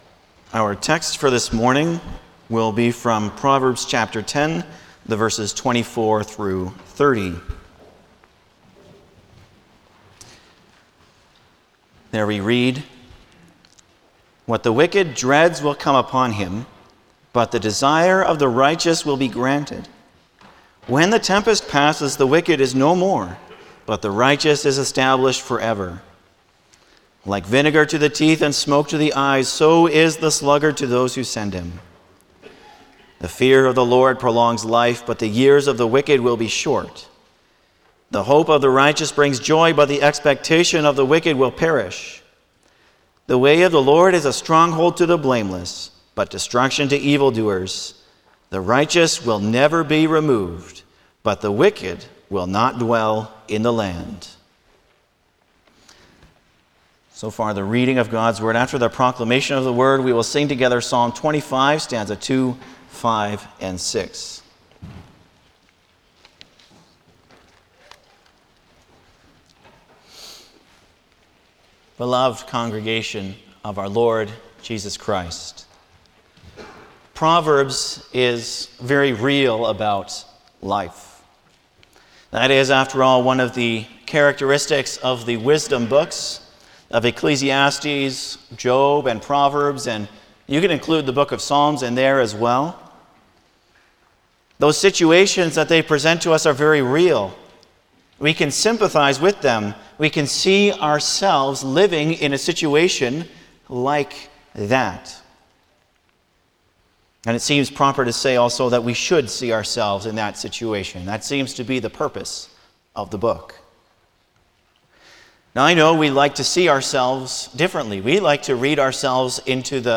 Passage: Proverbs 10:24-30 Service Type: Sunday morning
08-Sermon.mp3